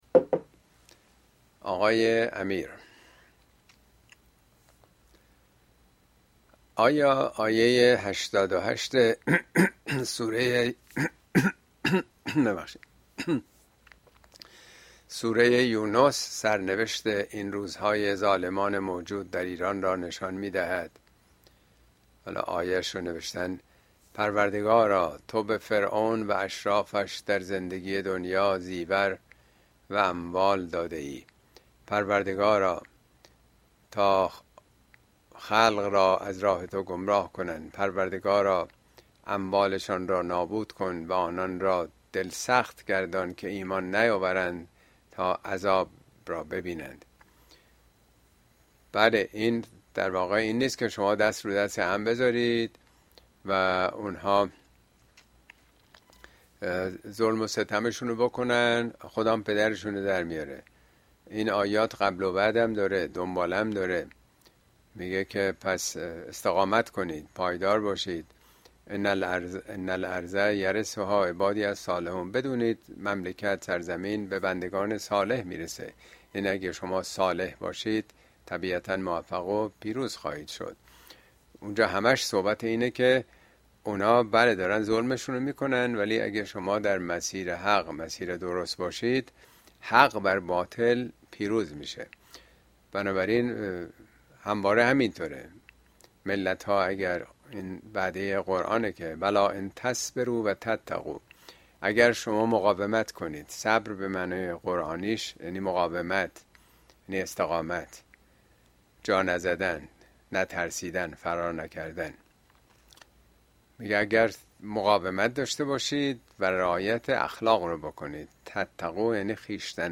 Speech Sweet Lesson from Bitter Failure
` موضوعات اجتماعى اسلامى عبرت شیرین از شکست تلخ اين سخنرانى به تاريخ ۲۵ سپتامبر ۲۰۲۴ در كلاس آنلاين پخش شده است توصيه ميشود براىاستماع سخنرانى از گزينه STREAM استفاده كنيد.